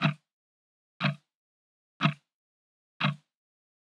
TickTock 01.wav